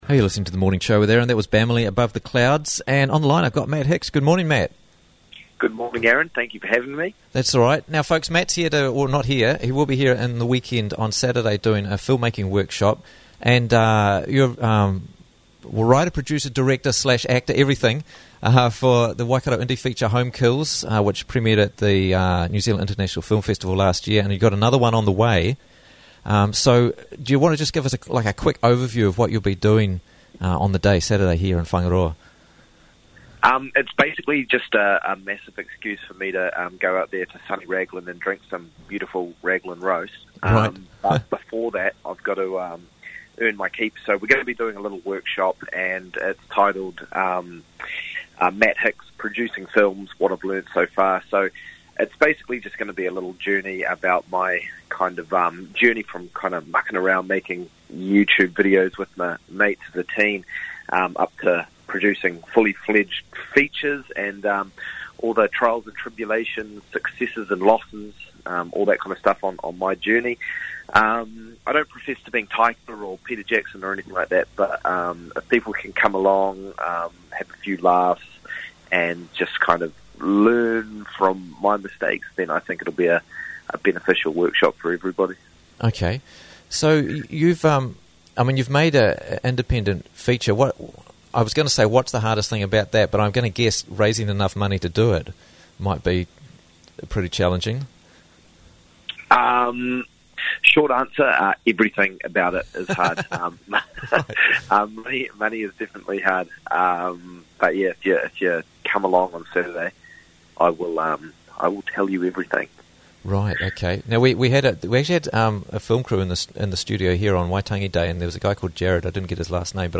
Interviews from the Raglan Morning Show